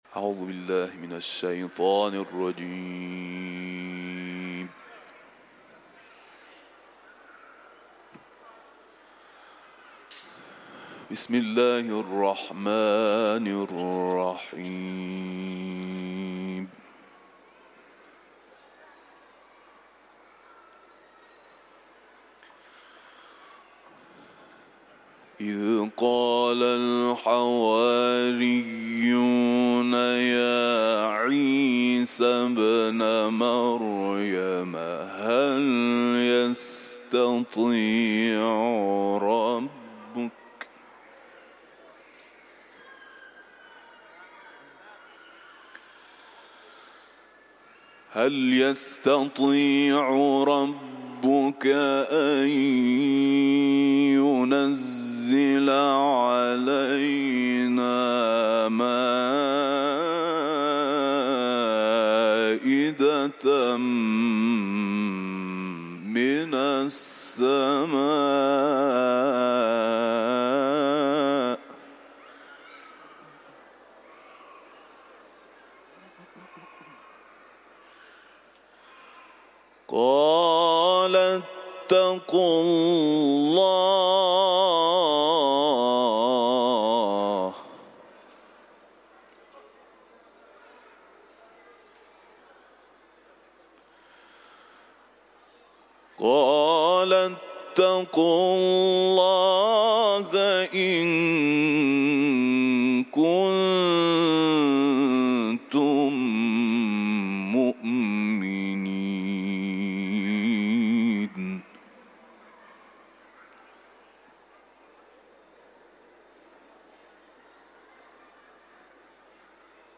Etiketler: İranlı kâri ، Kuran tilaveti ، Maide sûresi